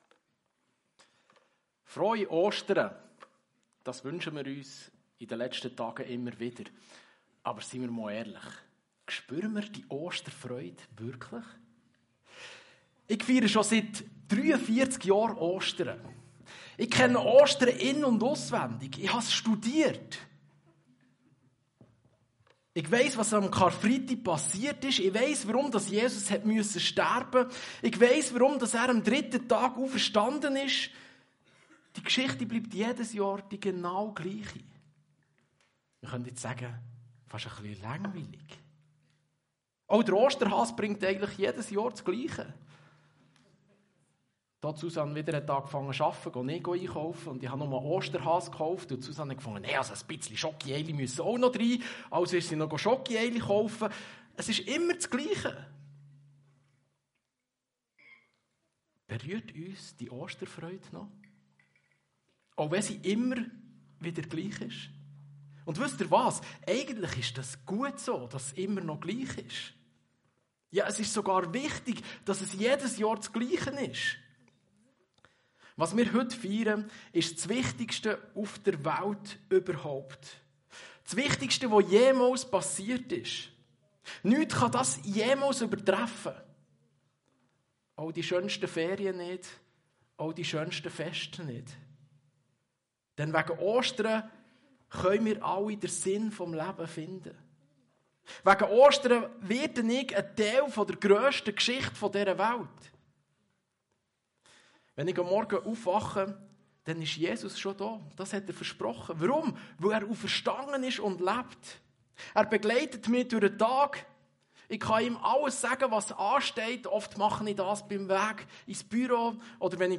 Eine predigt aus der serie "Open Topic."